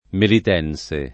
vai all'elenco alfabetico delle voci ingrandisci il carattere 100% rimpicciolisci il carattere stampa invia tramite posta elettronica codividi su Facebook melitense [ melit $ n S e ] etn. stor. (di Malta) — come term. med., febbre m. , lo stesso che maltese